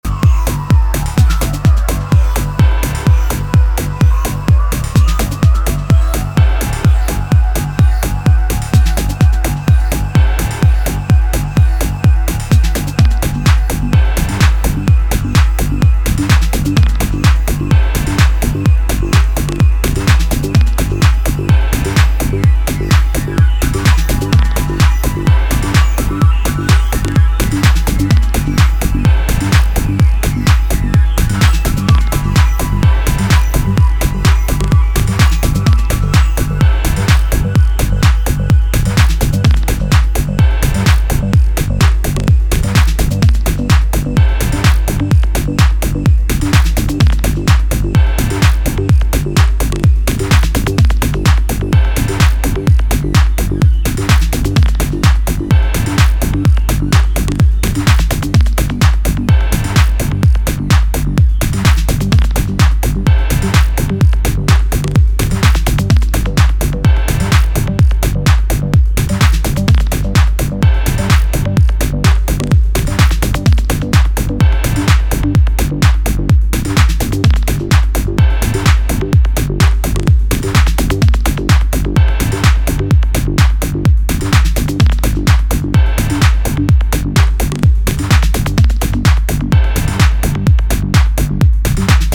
渦巻くシンセパルスとスウィングしたグルーヴ、金属質のスタブで抜群の引力を放つ